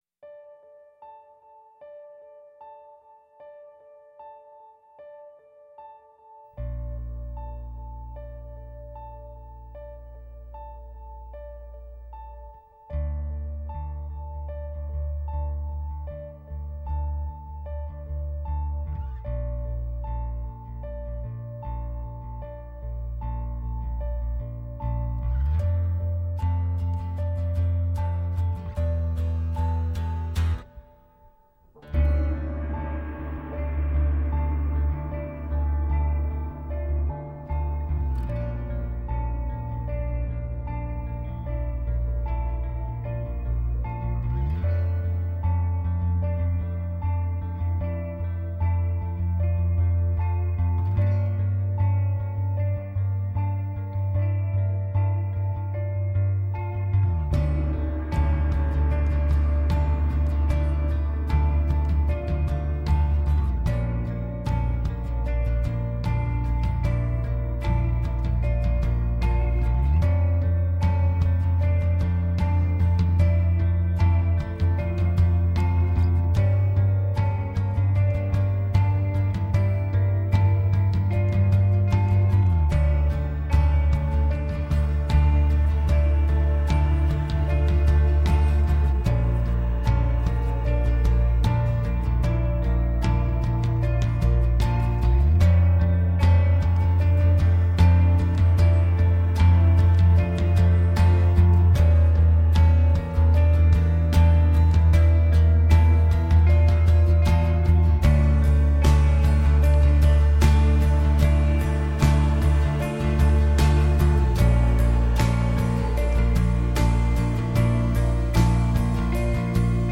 موسیقی های اینسترومنتال
آهنگ پست راک